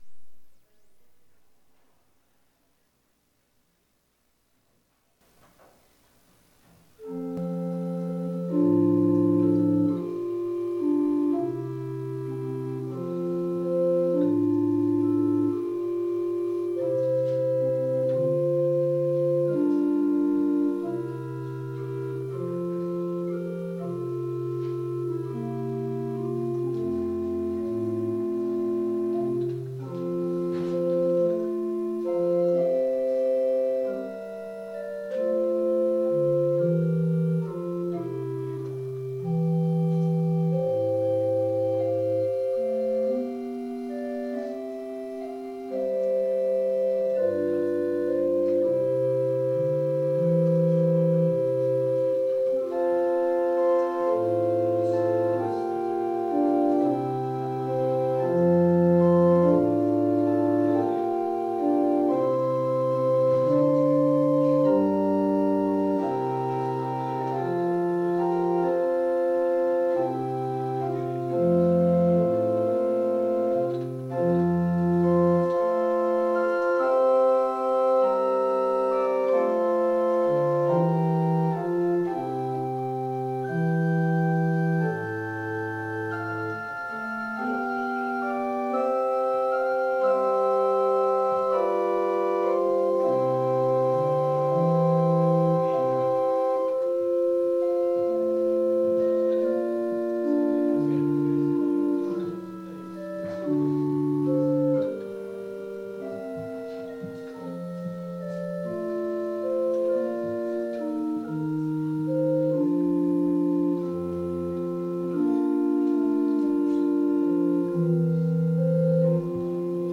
Jõulujumalateenistus (Rakveres)
Koosolekute helisalvestused